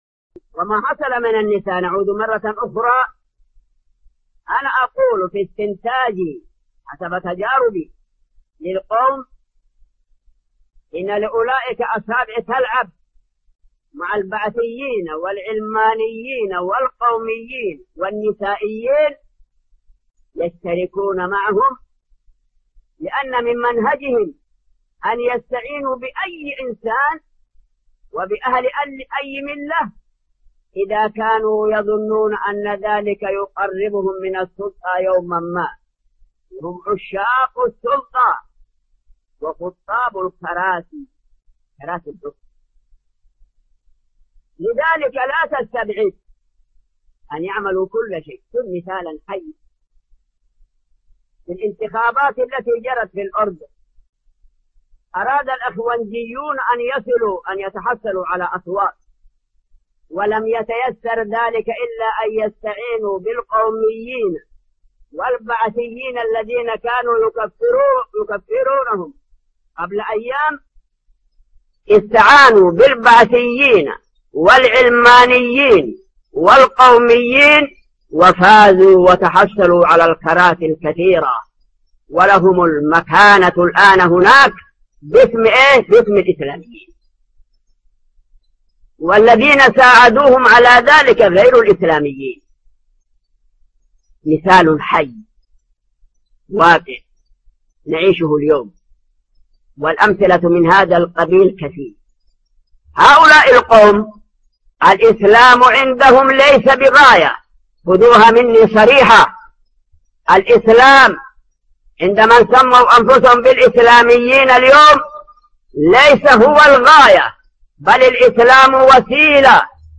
Title: الإسلام عند الحزبيين وسيلة للوصول إلى الكراسي Album: موقع النهج الواضح Length: 4:05 minutes (1.01 MB) Format: MP3 Mono 22kHz 32Kbps (VBR)